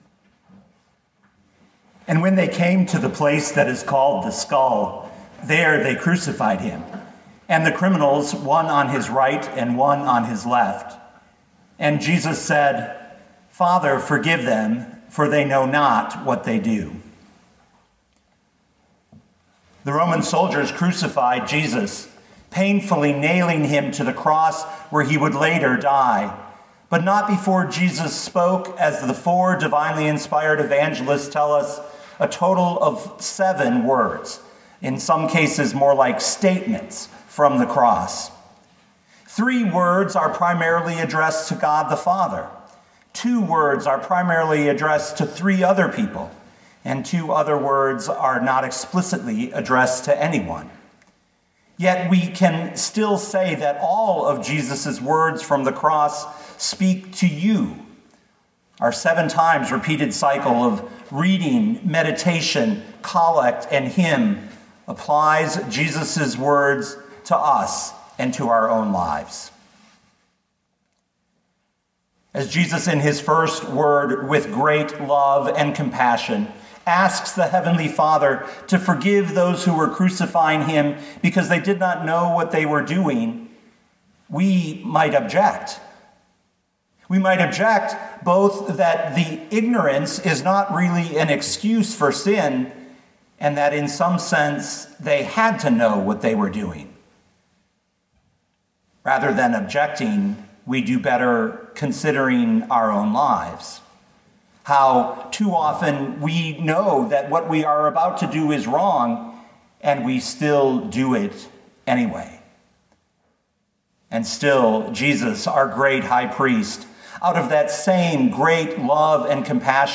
Luke 23:46 Listen to the sermon with the player below, or, download the audio.